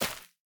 assets / minecraft / sounds / block / hanging_roots / break2.ogg